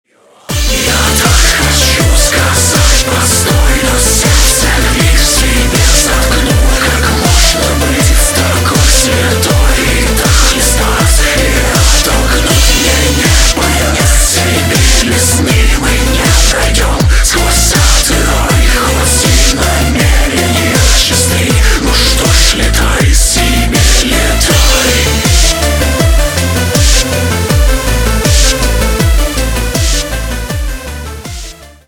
• Качество: 192, Stereo
мужской голос
атмосферные
EBM
Industrial
Dark Electro
Aggrotech
шепот